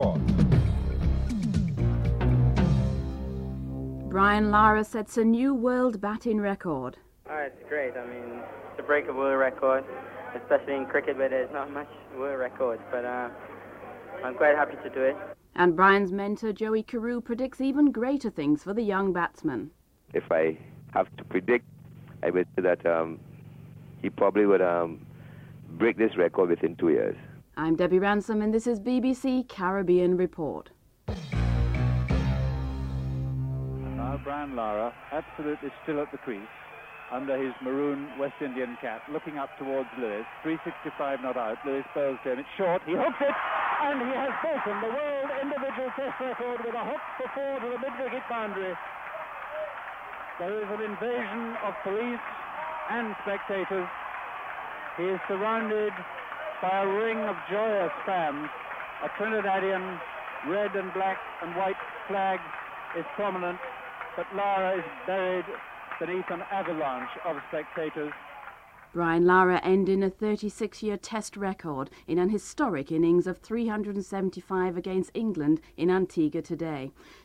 Recap of headlines & theme music (14:17-14:46)